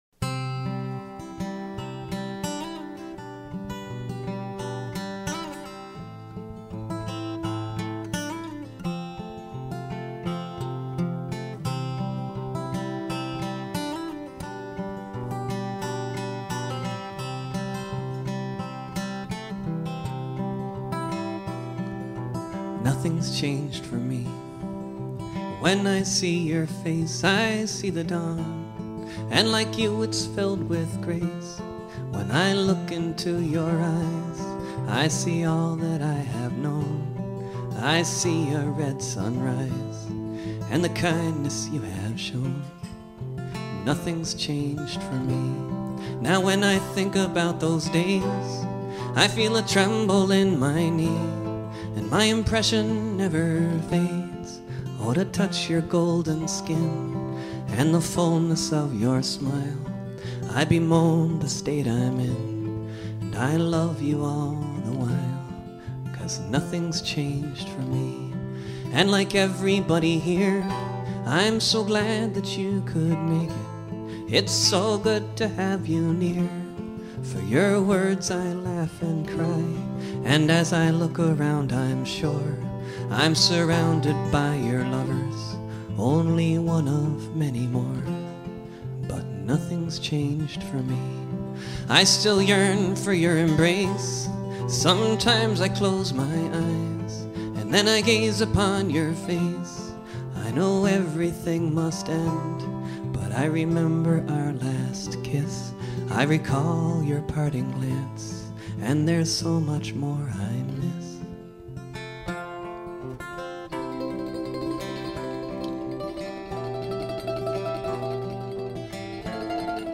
It’s a love song.